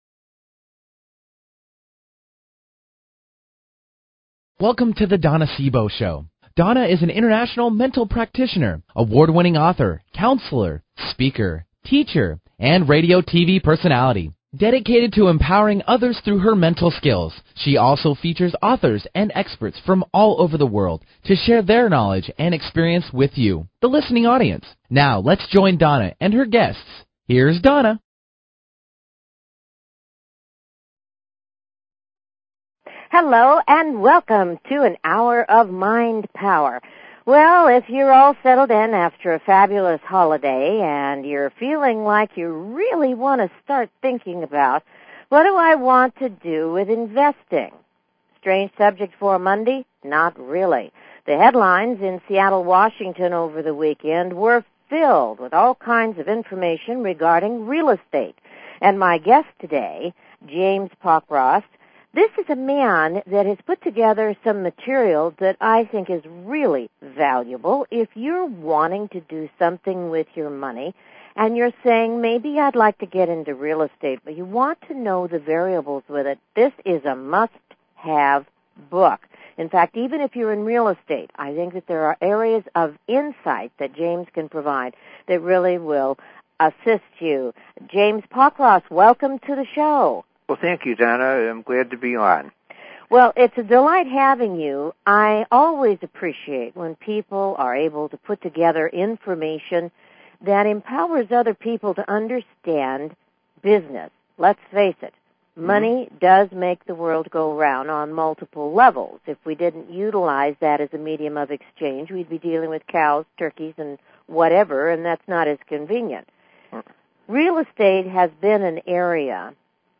Talk Show Episode, Audio Podcast
Join us for an informative chat.
Callers are welcome to call in for a live on air psychic reading during the second half hour of each show.